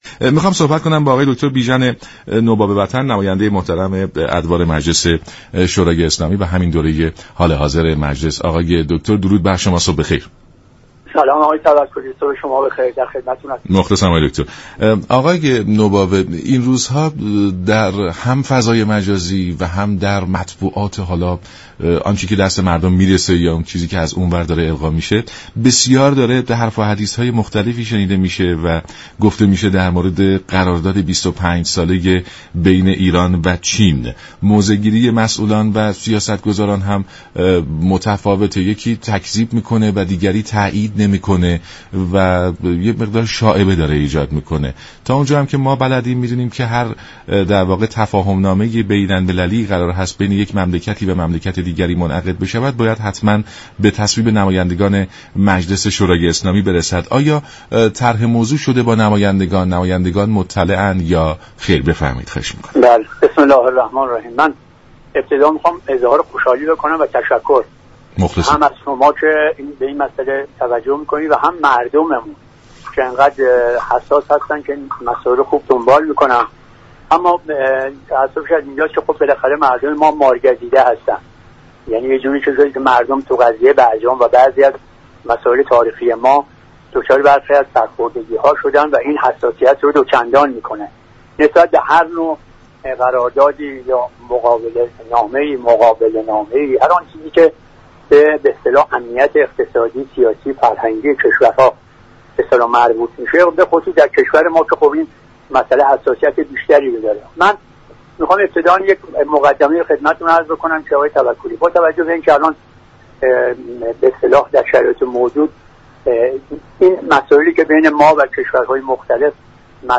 به گزارش شبكه رادیویی ایران، دكتر «بیژن نوباوه» نماینده مردم تهران، ری و شمیرانات در مجلس شورای اسلامی در برنامه «سلام صبح بخیر» رادیو ایران درباره قرارداد 25 ساله ایران و چین گفت: مجلس شورای اسلامی هنوز از جزئیات این قرارداد آگاهی ندارد.